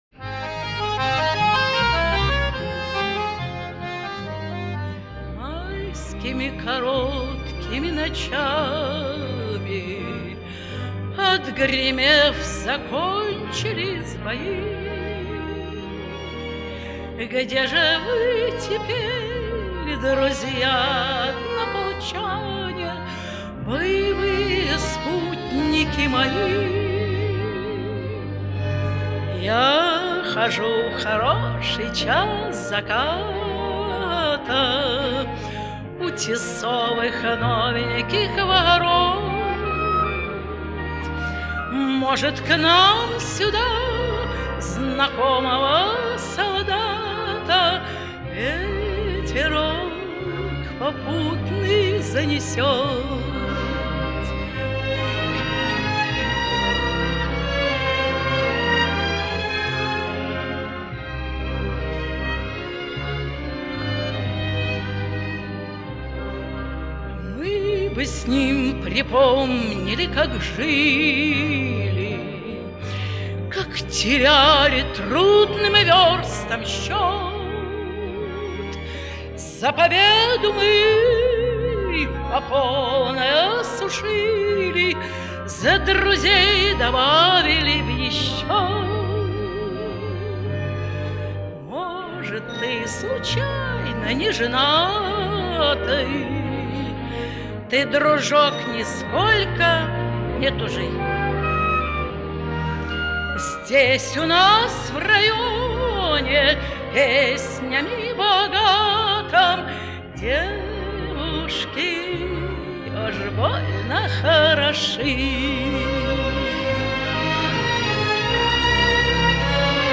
Её исполнению присуща глубокая задушевность.